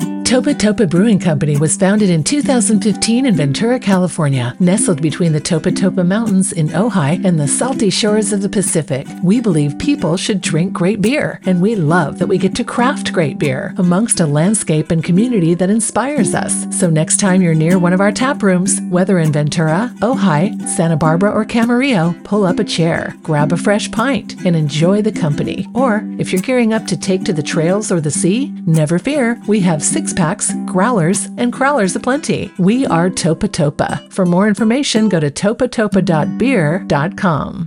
Commercial Lifestyle
My voiceover style is consistently described as authentic, calm, and trustworthy, offering a smooth, conversational and friendly delivery that truly connects.
Operating from a professional home studio utilizing Adobe Audition, I meticulously record, produce and deliver pristine, polished voiceover recordings with exceptional attention to detail.